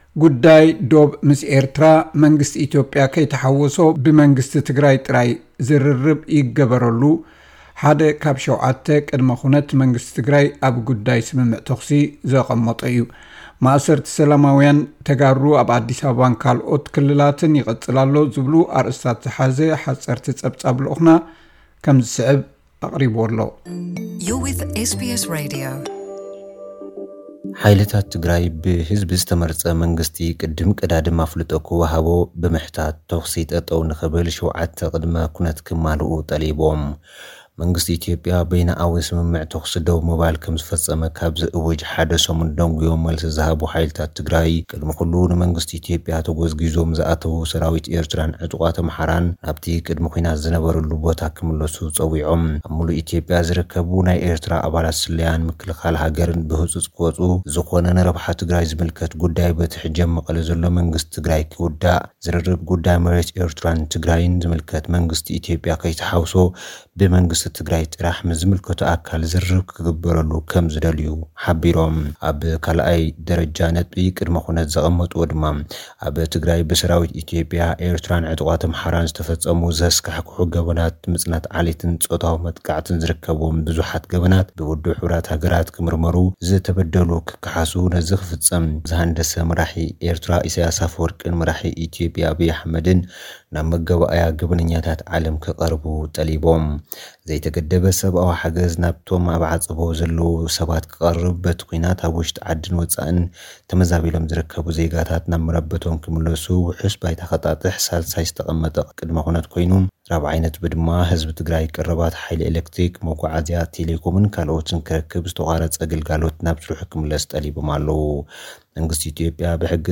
ካብ ኣርእስታት ጸብጻብ ማእሰርቲ ሰላማውያን ተጋሩ ኣብ ኣዲስ ኣበባን ካልኦት ክልላትን ይቕጽል ኣሎ። ዝብሉ ኣርእስታት ዝሓዘ ሓጸርቲ ጸብጻባት ልኡኽና ክቐርብዩ።